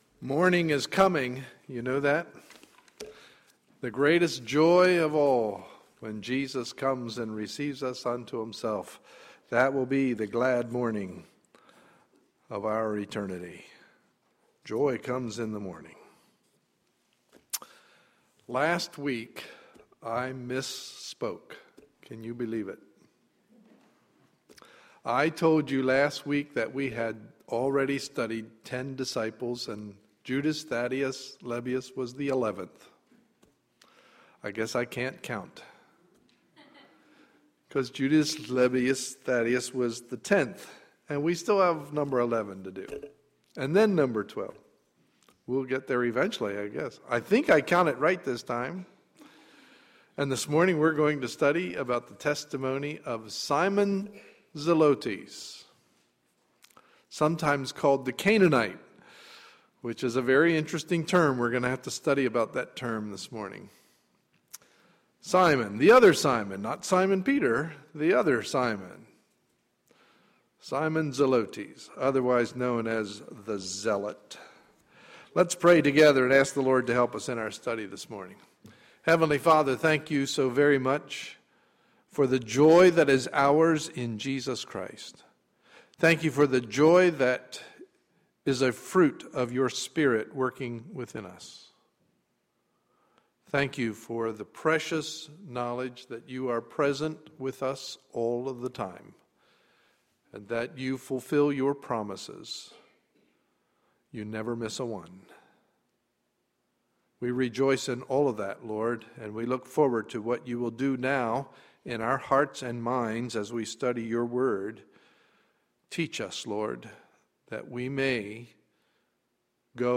Sunday, August 19, 2012 – Morning Message